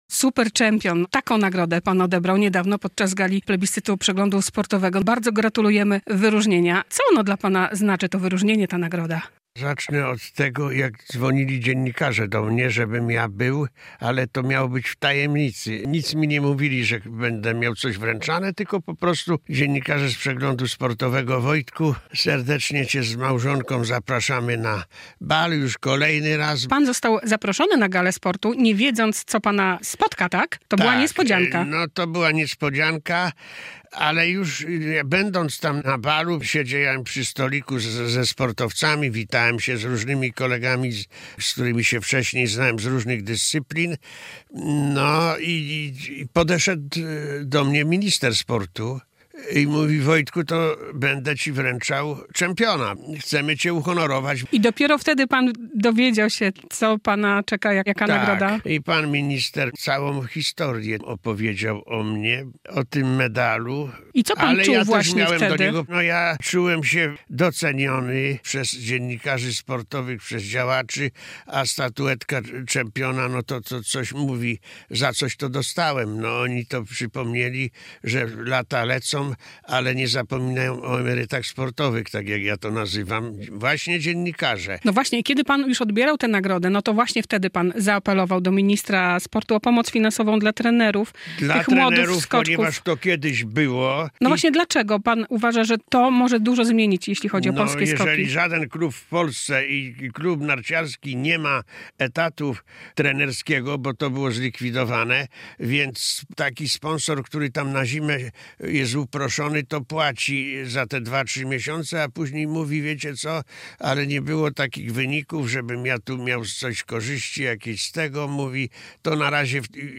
Radio Białystok | Gość | Wojciech Fortuna - mistrz olimpijski w skokach narciarskich